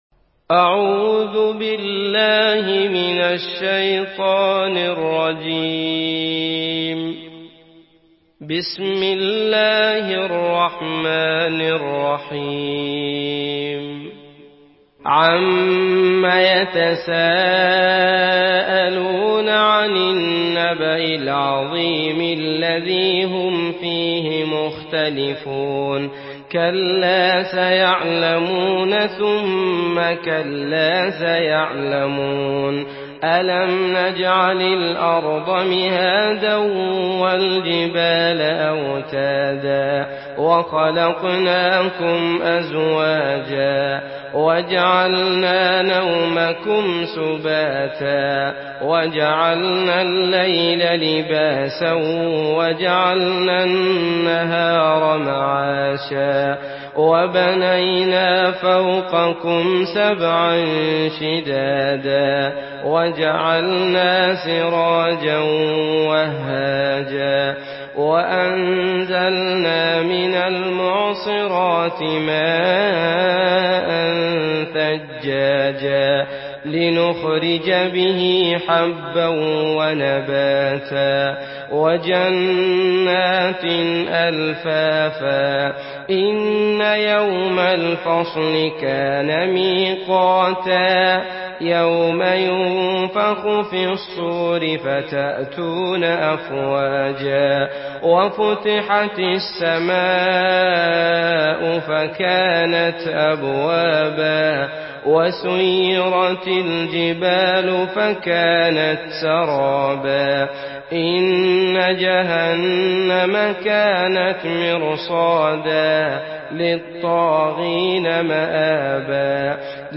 مرتل